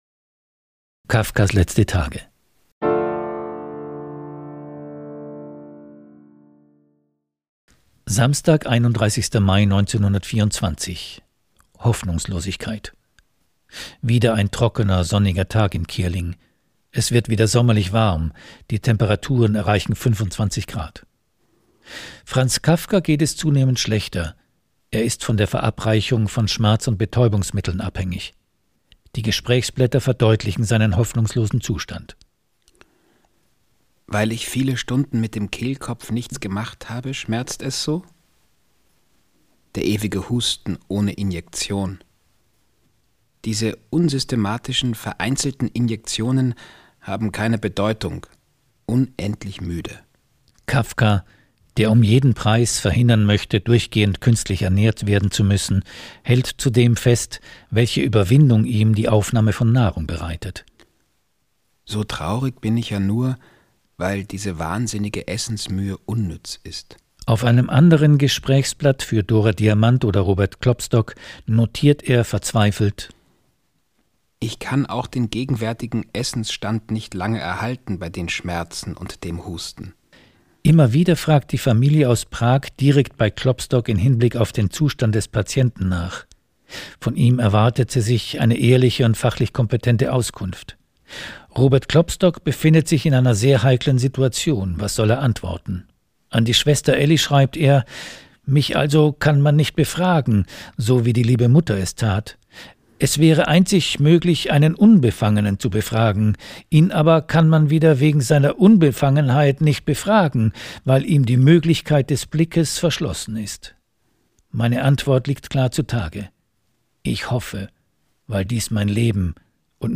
Kafka: Robert Stadlober Erzähler: Nikolaus Kinsky Studio: